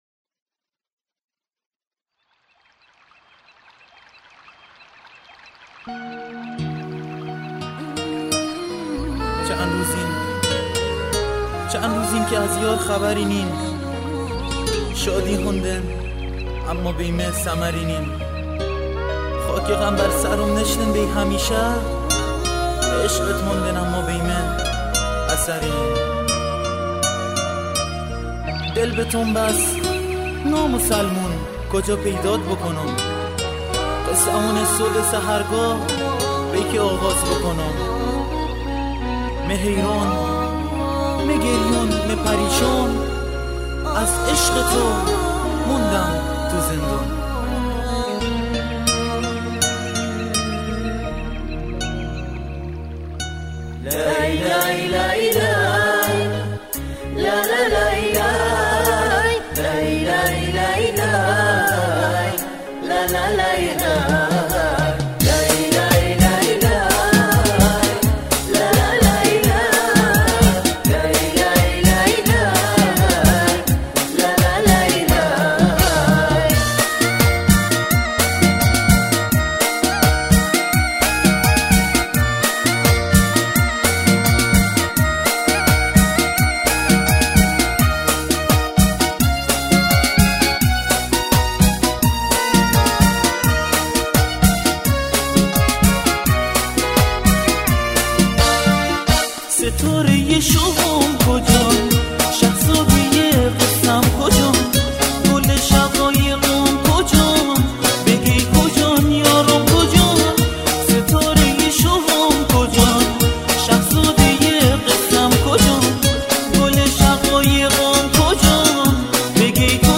تمام تراک‌های این آلبوم به سبک و گویش بندری است.